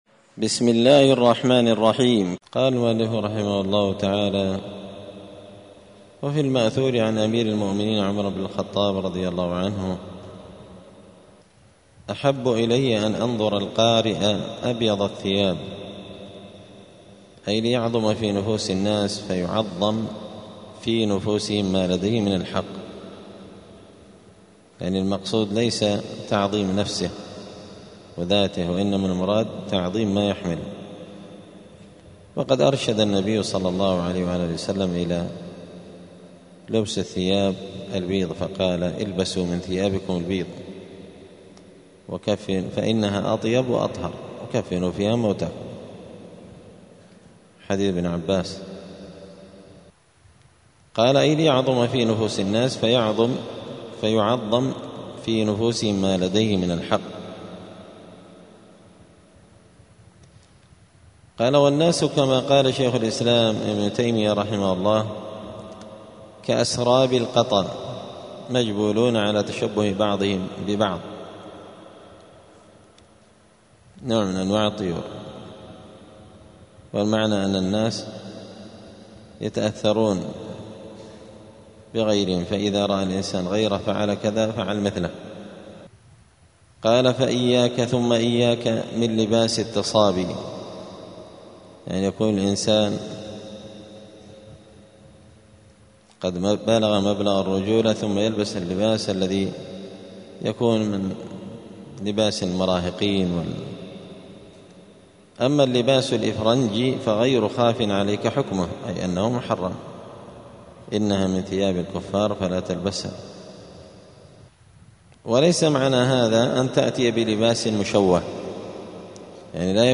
الأحد 27 ذو القعدة 1446 هــــ | الدروس، حلية طالب العلم، دروس الآداب | شارك بتعليقك | 6 المشاهدات